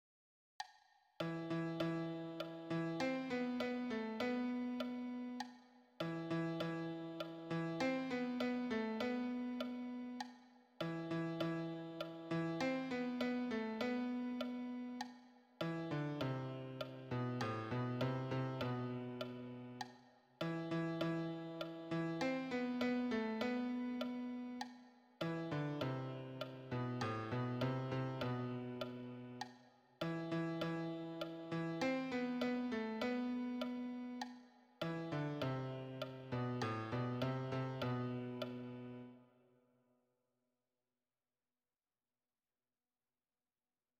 Répétition SATB par voix
Messe Saint Germain_Refrain_basse.mp3